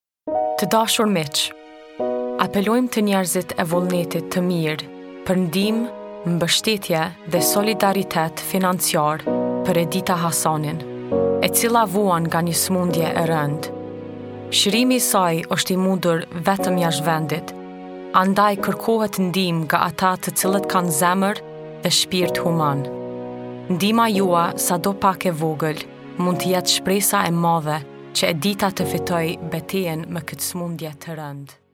Albanian, Female, 20s-30s